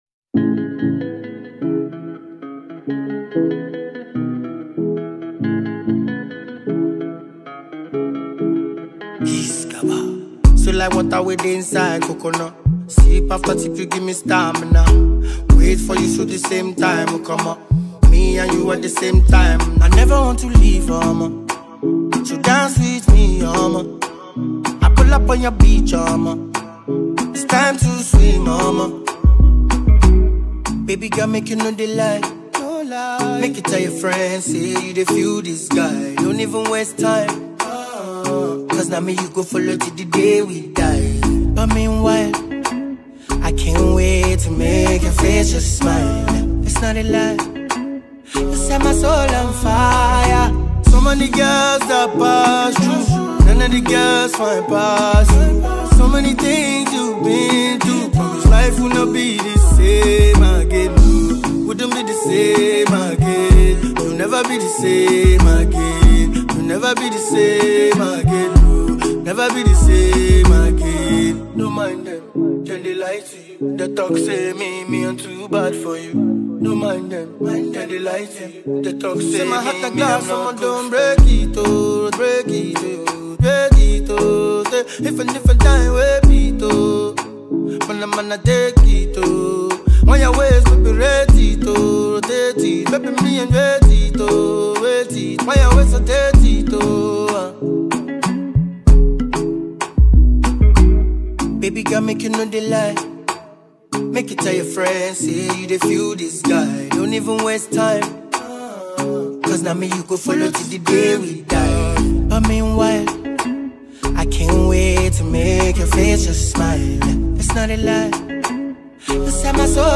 is a mid tempo tune